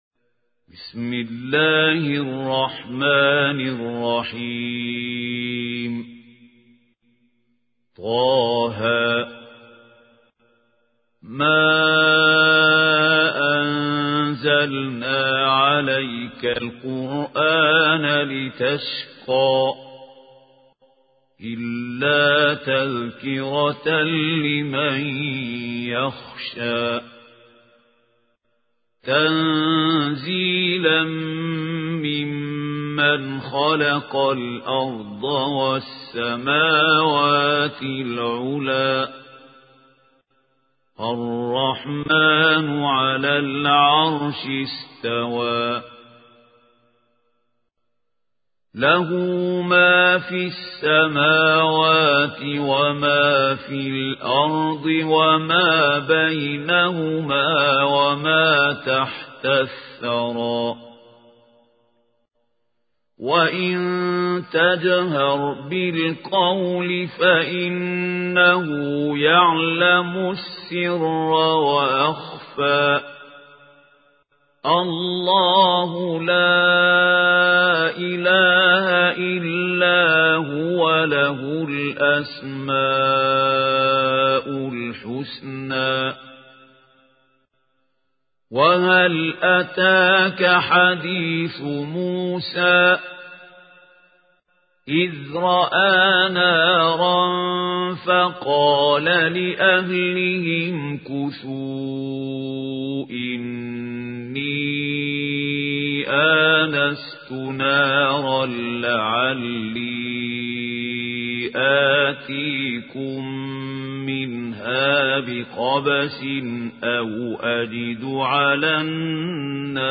القارئ: الشيخ خليل الحصري